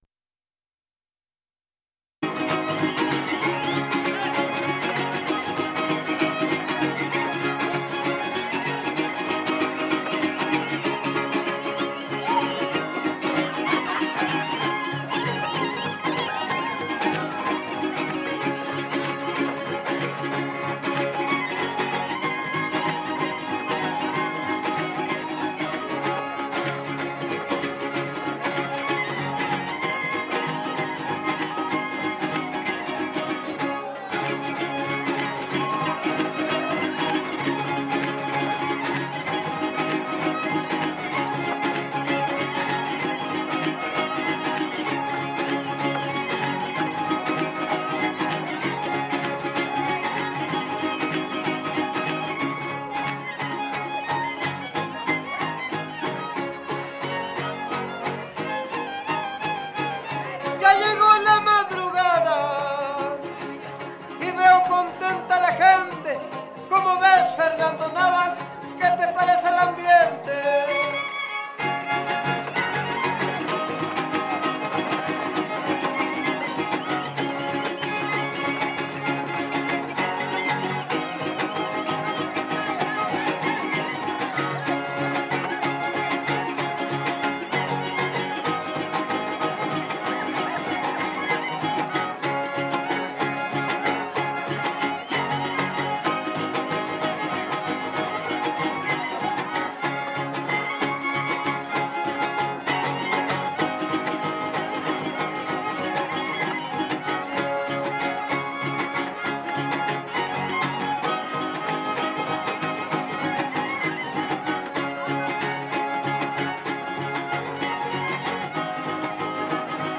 01 Secuencia arribeña
Topada ejidal: Cárdenas, San Luis Potosí